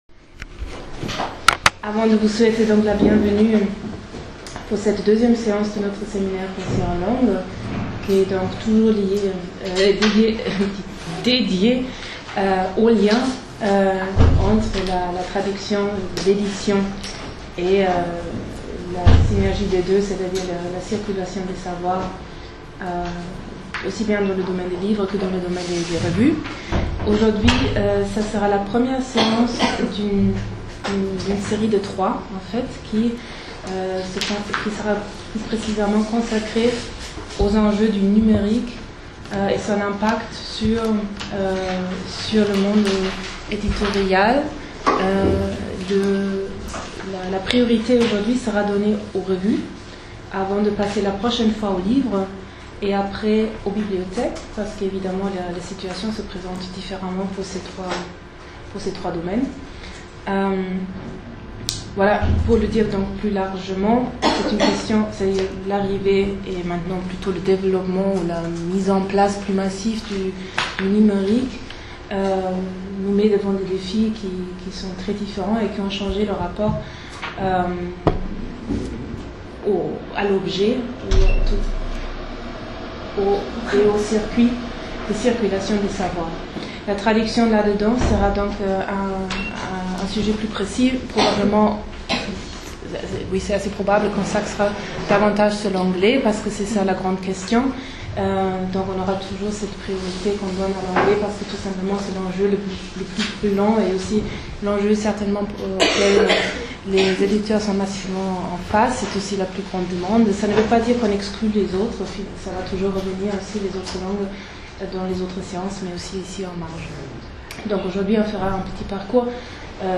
L’enregistrement du cours d’introduction qui s’est tenu le 8 décembre 2016 peut être écouté ici :